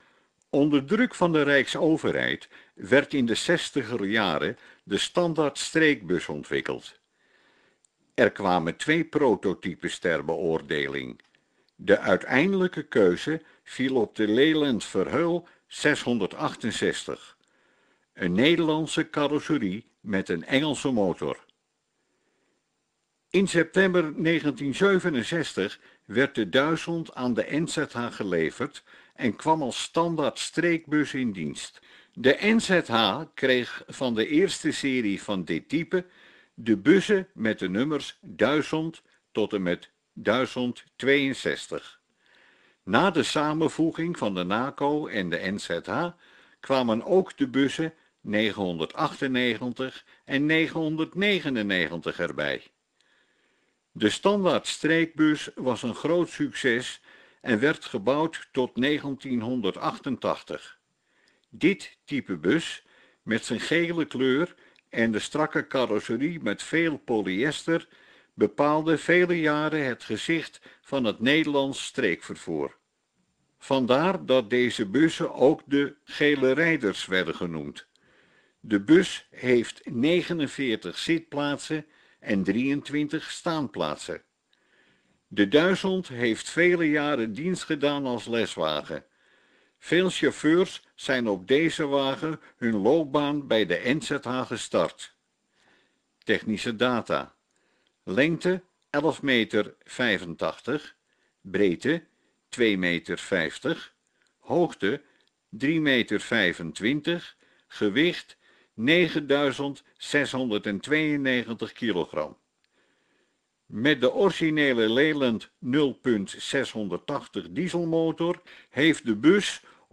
Autobus-Lyland-1000.mp3